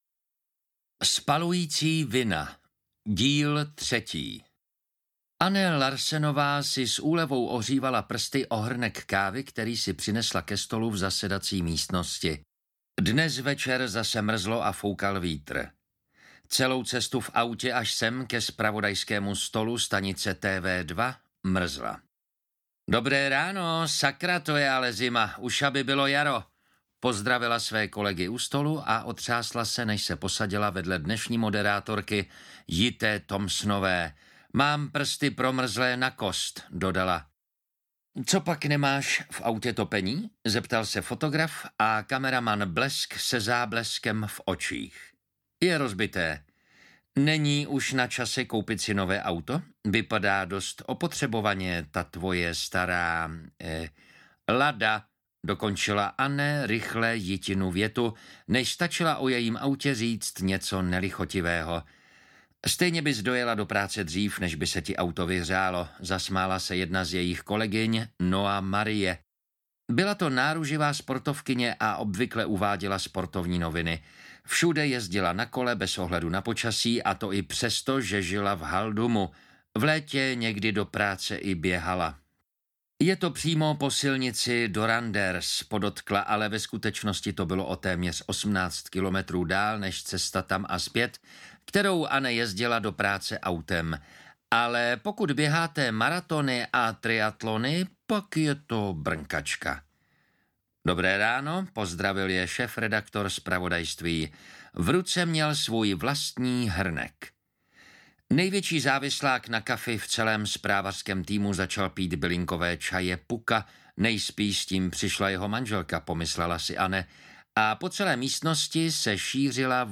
Spalující vina - Díl 3 audiokniha
Ukázka z knihy
spalujici-vina-dil-3-audiokniha